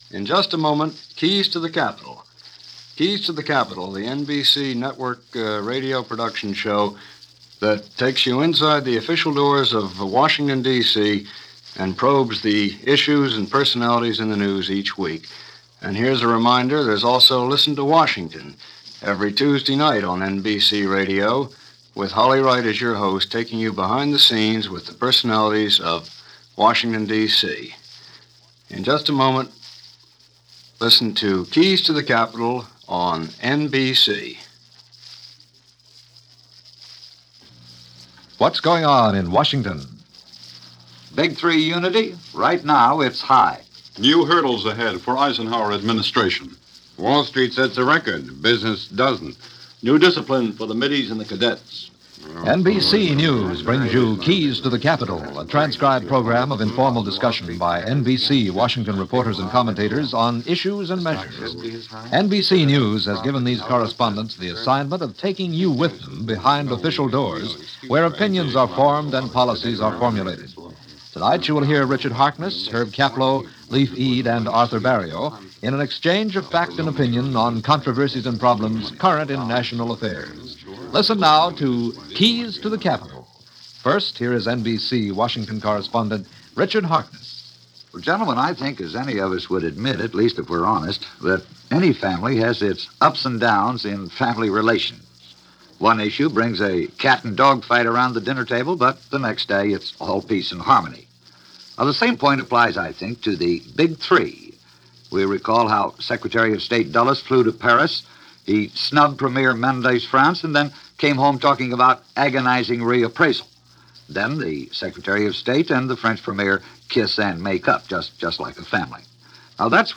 Pierre Mendes-France And The Big 3 - 1954 - Weekly discussion program "Keys To The Capitol" from NBC Radio.